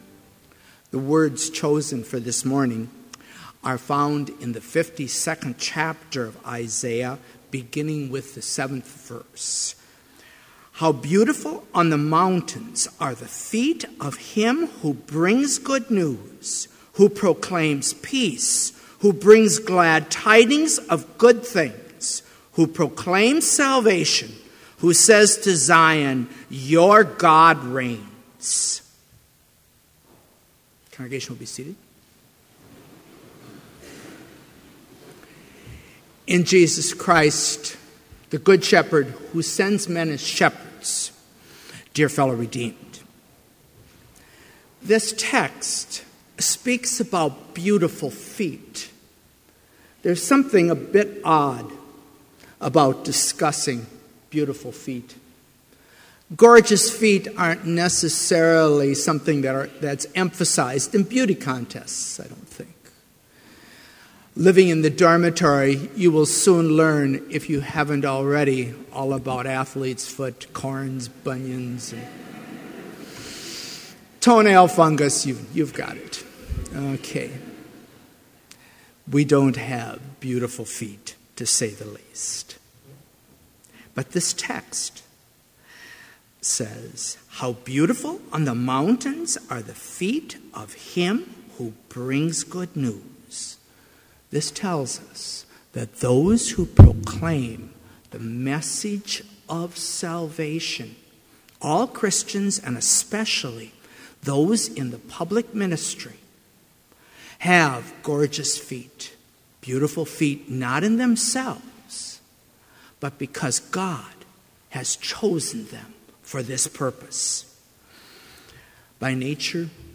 Complete service audio for Chapel - August 27, 2015